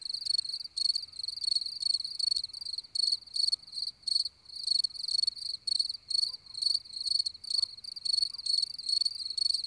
1蛐蛐.wav